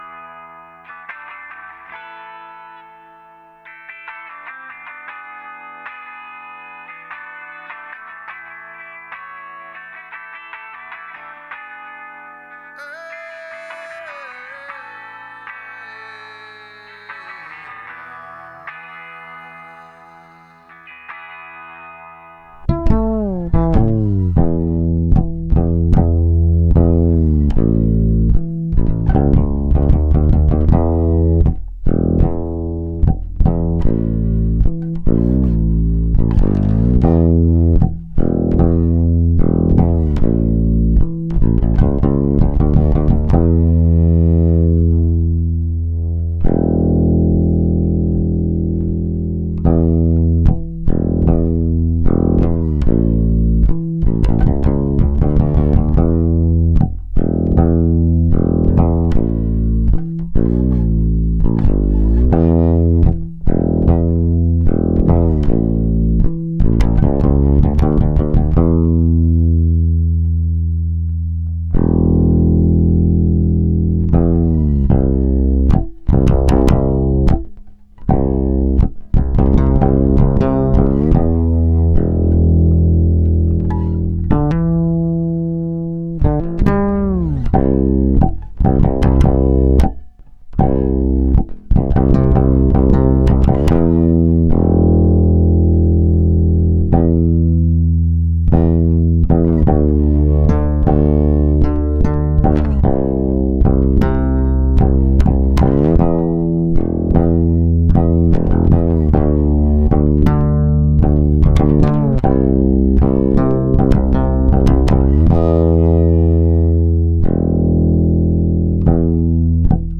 bass only